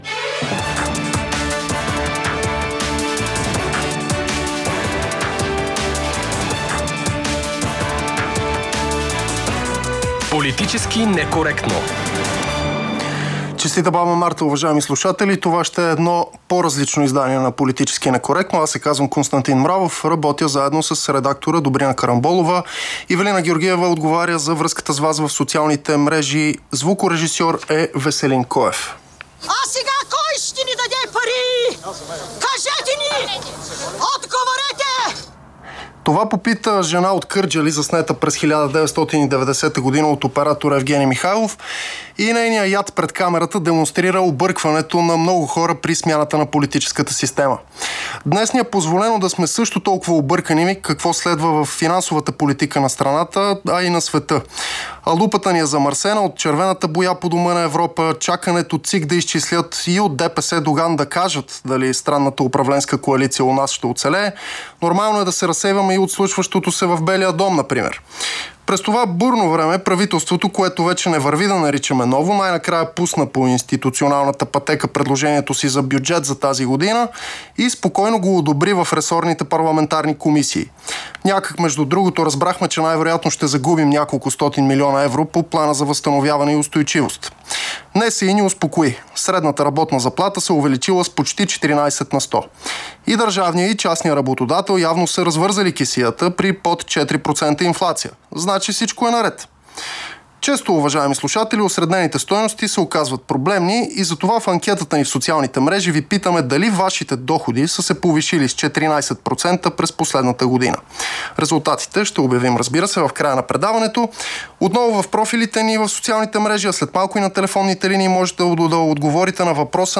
Събеседници са икономистите